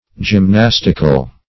gymnastical - definition of gymnastical - synonyms, pronunciation, spelling from Free Dictionary
\Gym*nas"tic*al\ (j[i^]m*n[a^]s"t[i^]*kal), a. [L. gymnasticus,
gymnastical.mp3